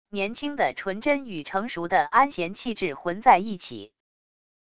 年轻的纯真与成熟的安闲气质混在一起wav音频